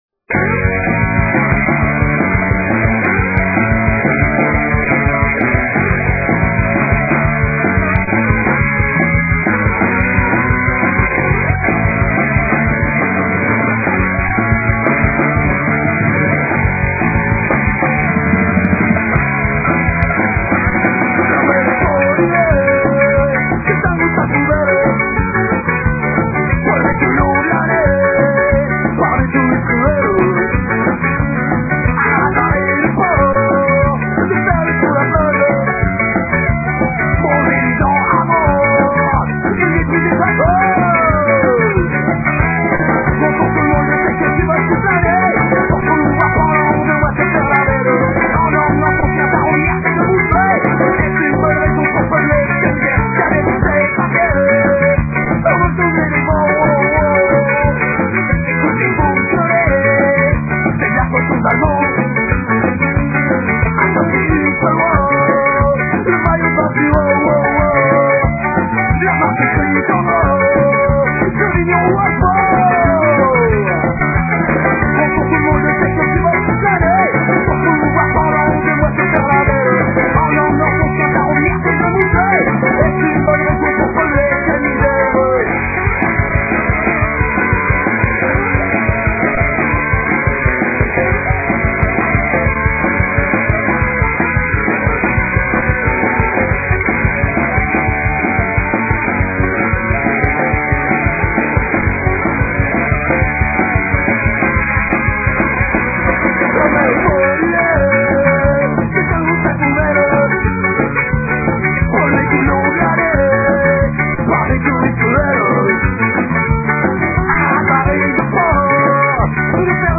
Les fichiers sont en mp3 et de qualité assez mauvaise .
un rythme ska, du sax mais ça bouge quand même pas mal!!